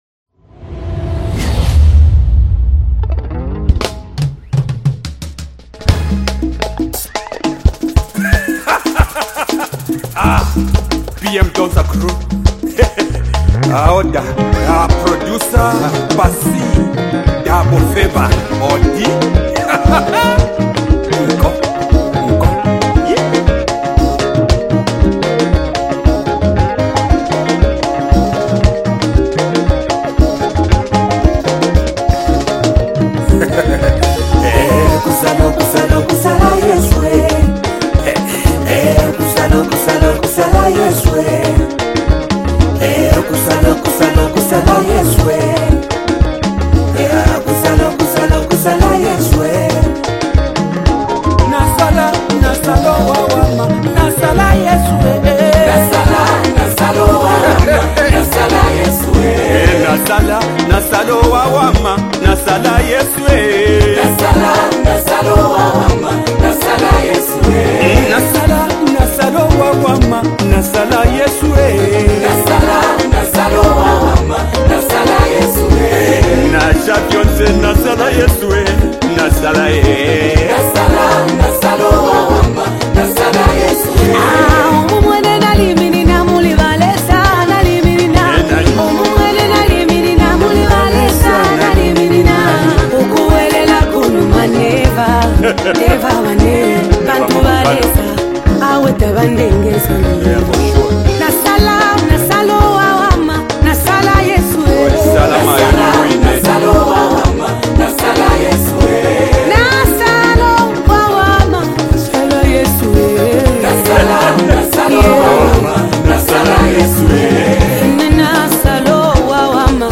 A unique mix of Rumba and worship sounds
📅 Category: Latest Zambian Rumba Worship Song